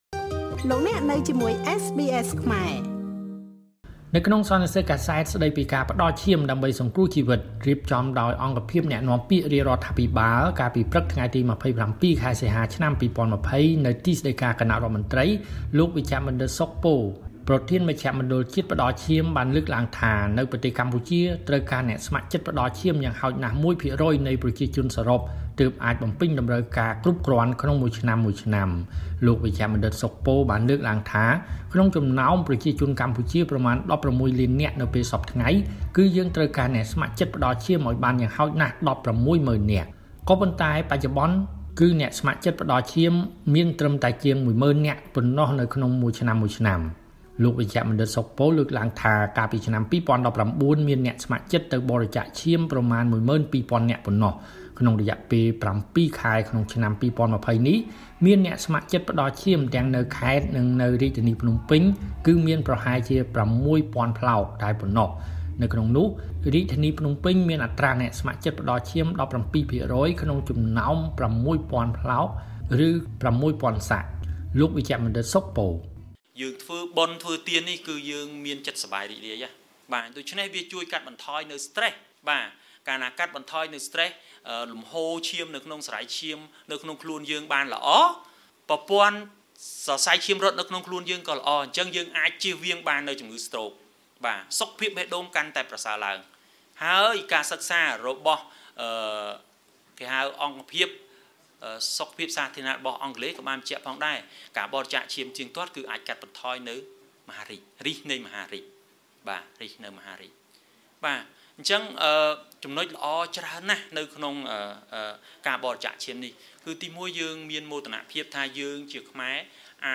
ក្នុងសន្និសីទកាសែត ស្តីពី ការផ្តល់ឈាម ដើម្បីសង្គ្រោះជីវិត រៀបចំដោយអង្គភាពអ្នកនាំពាក្យរាជរដ្ឋាភិបាល